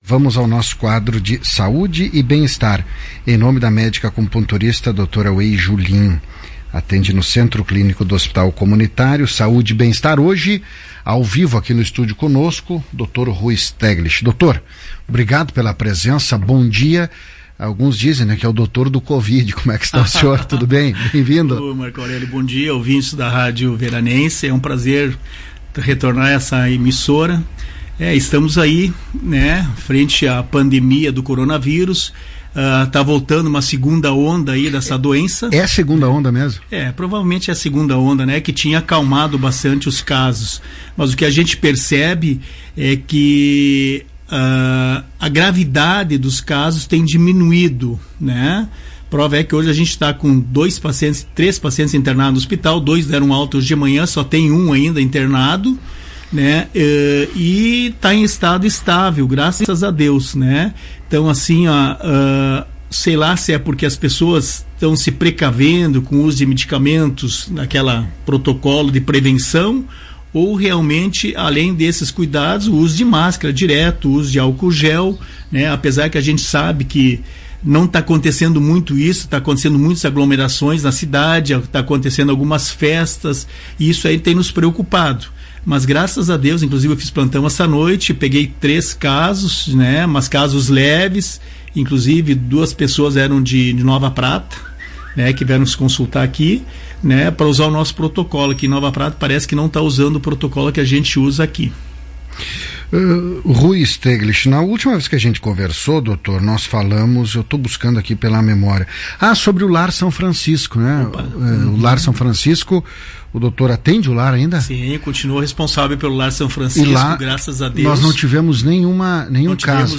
Médico fala da segunda onda da Covid-19 na região